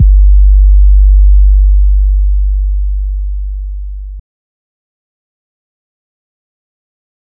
808s
DMV3_808 9.wav